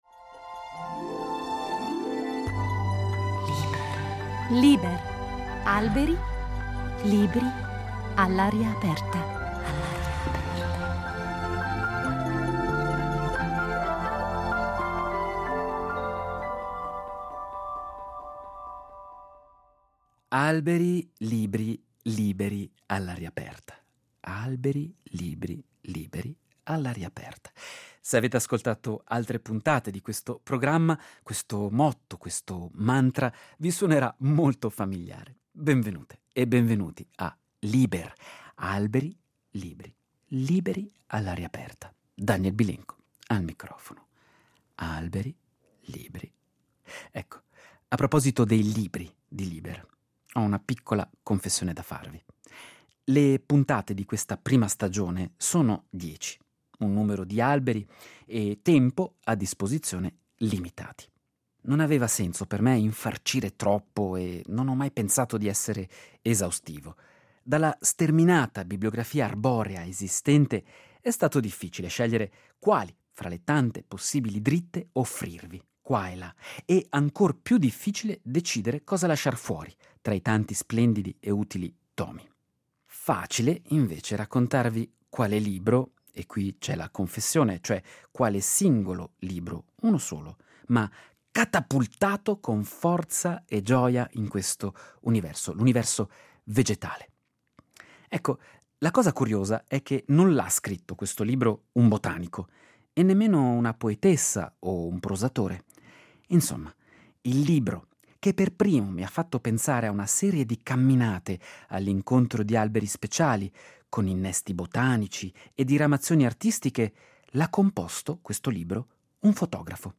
legge la sua poesia